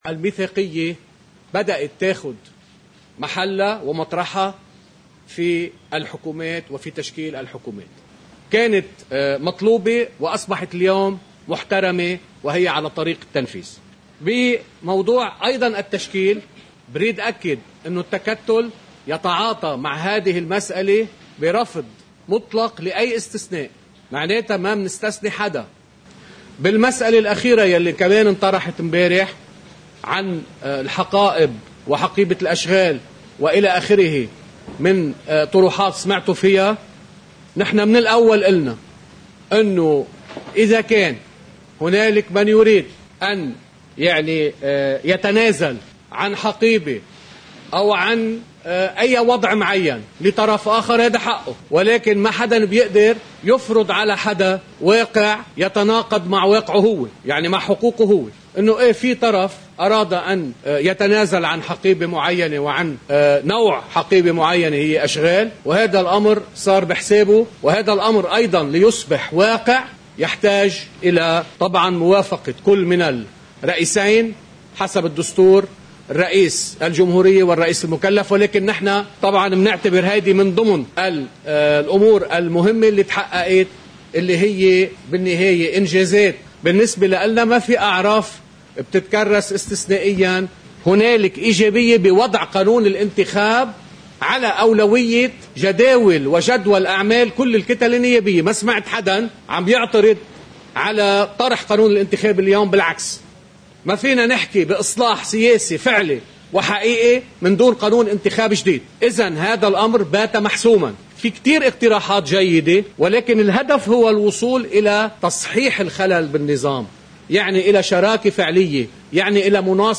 مقتطفات من حديث النائب كنعان بعد الإجتماع الأسبوعي لتكتّل التغيير والإصلاح في الرابية: